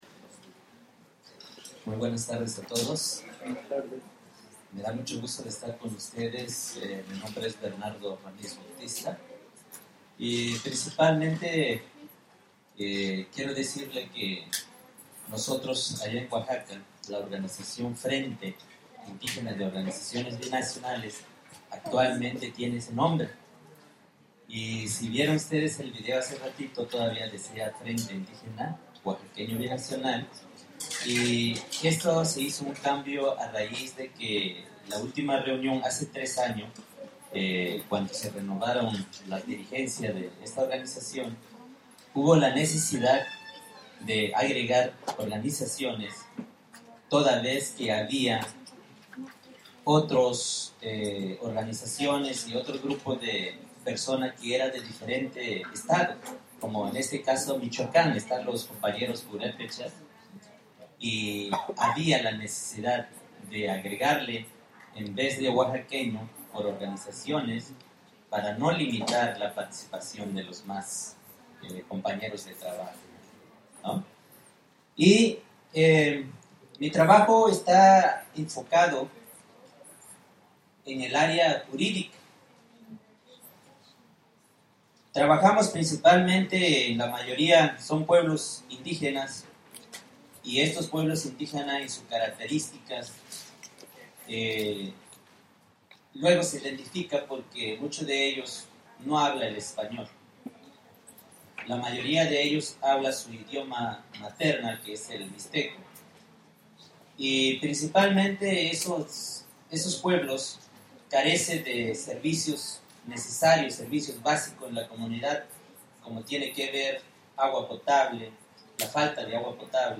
gave presentations in Greenfield and at UC Santa Cruz on indigenous Mexican migration to the U.S. and its impact in the communities of origin, the current political situation in Oaxaca, the role of women in the movement for social justice in Oaxaca, and current challenges of indigenous governing community institutions in Oaxaca. Audio recordings of the presentations